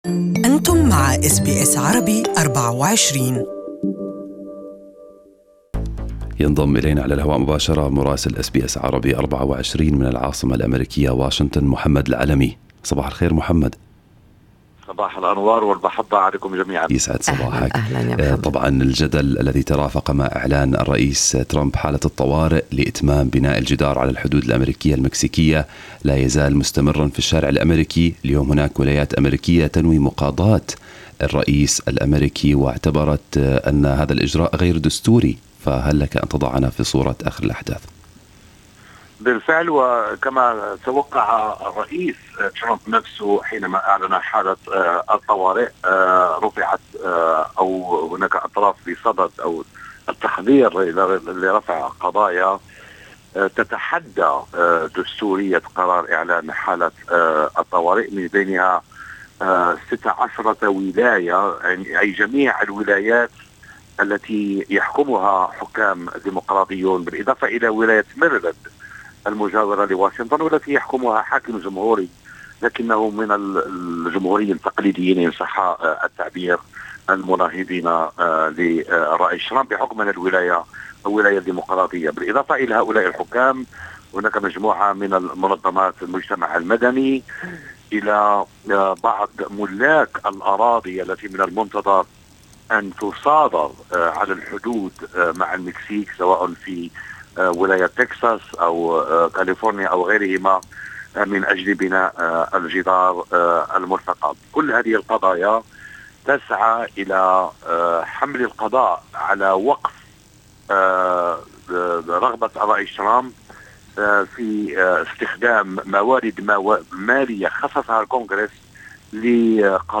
استمعوا إلى تقرير مراسلنا في واشنطن